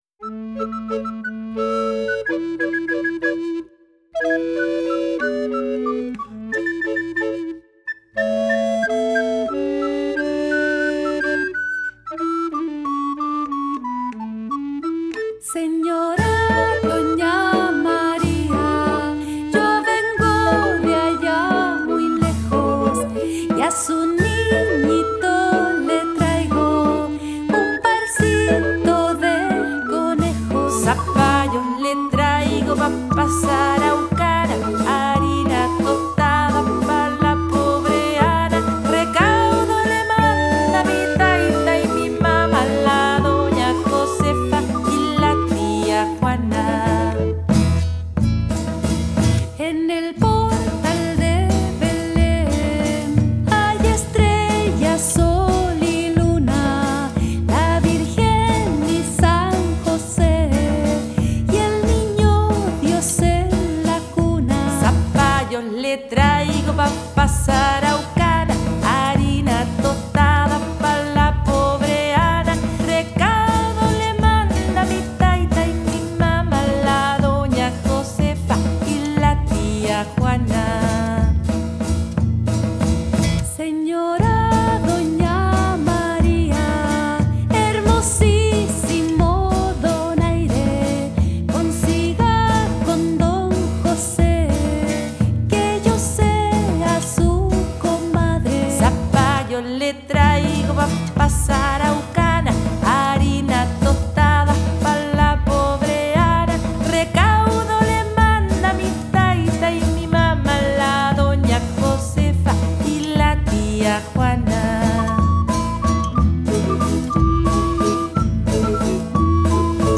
Interpretación musical
Canto
Música tradicional